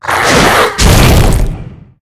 monsterpunch.ogg